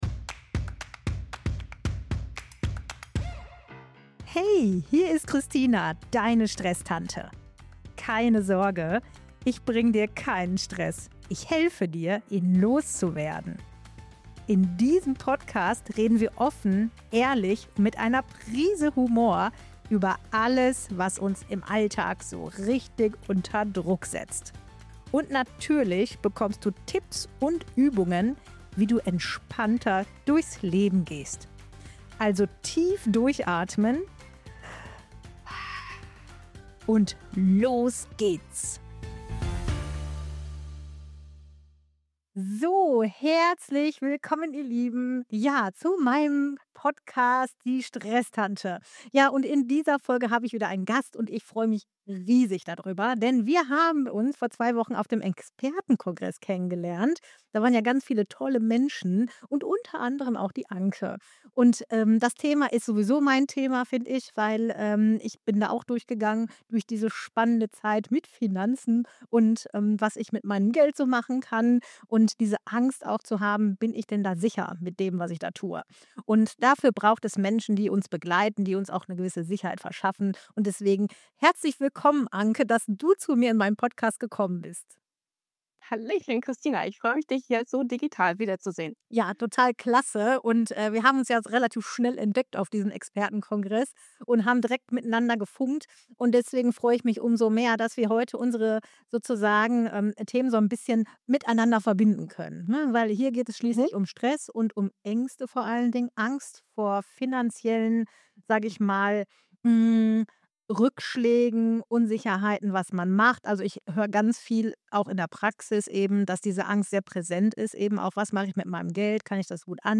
(ProvenExpert) Ein sachliches und wichtiges Gespräch für alle, die ihre finanzielle Zukunft bewusster gestalten möchten.